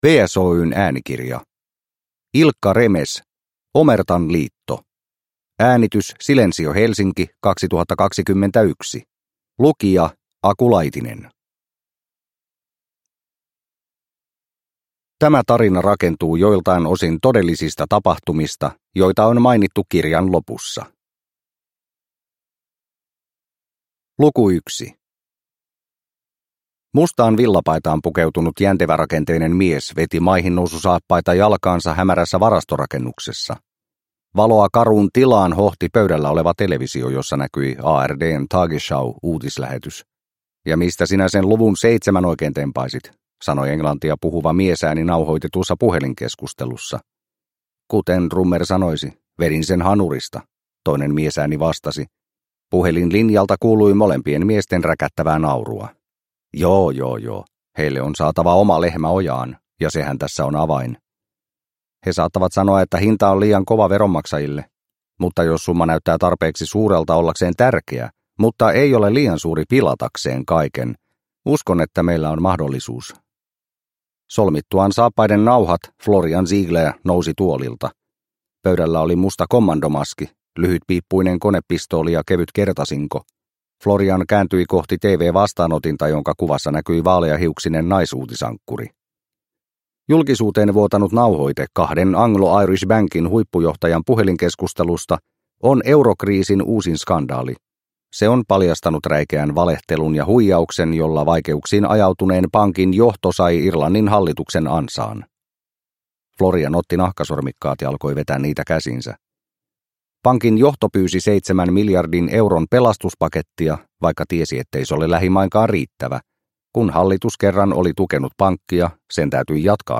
Omertan liitto – Ljudbok – Laddas ner